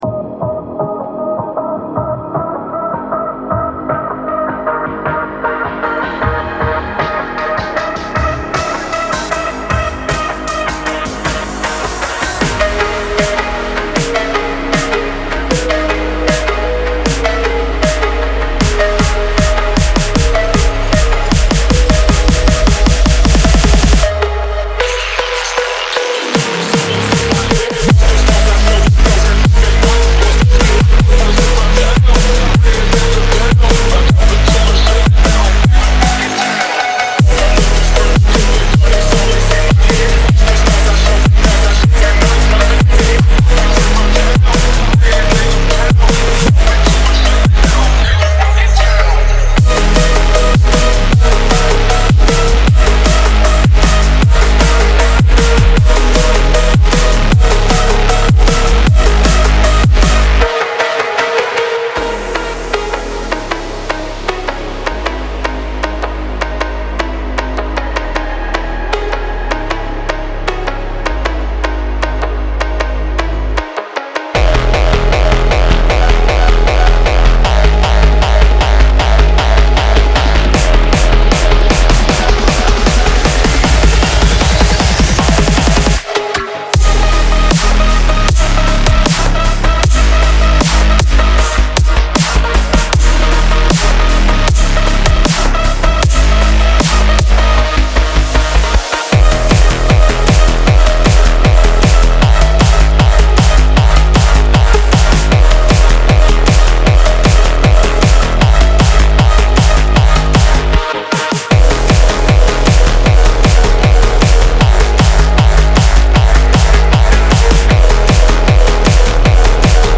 BPM155-155
Audio QualityPerfect (High Quality)
Phonk song for StepMania, ITGmania, Project Outfox
Full Length Song (not arcade length cut)